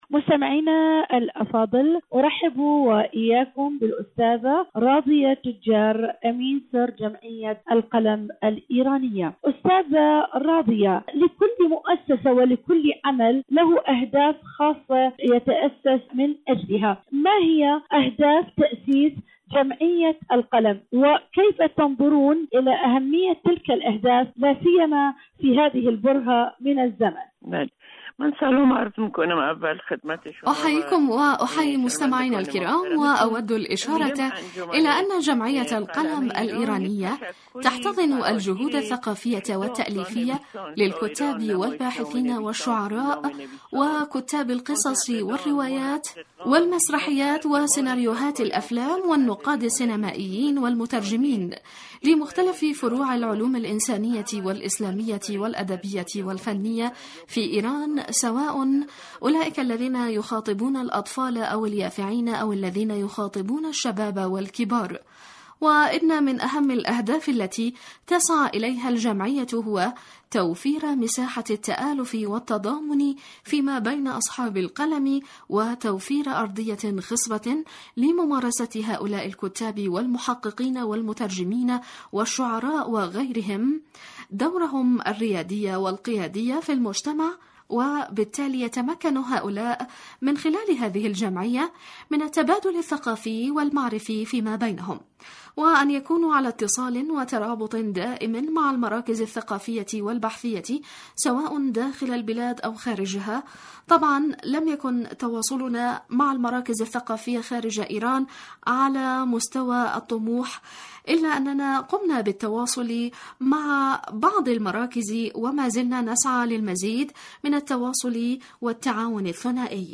جمعية القلم الثقافية في جمهورية إيران الإسلامية.. مقابلة